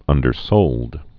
(ŭndər-sōld)